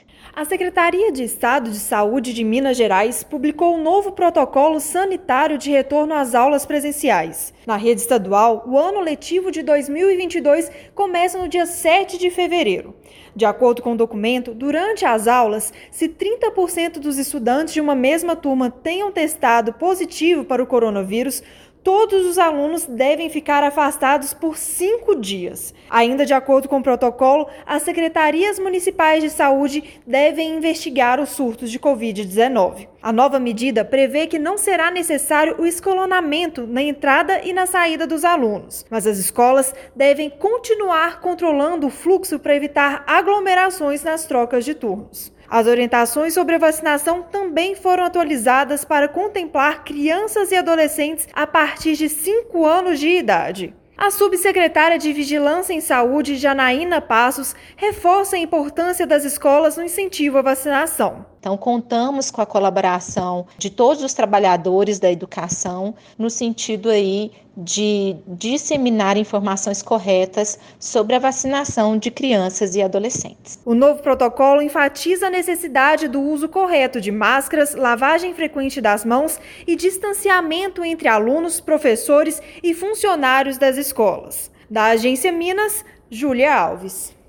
[RÁDIO] Saúde divulga novo protocolo de retorno às atividades escolares presenciais
Documento destaca importância da vacinação de adultos, adolescentes e crianças e revoga algumas restrições das escolas sem impacto na segurança sanitária de trabalhadores e alunos. Ouça a matéria de rádio.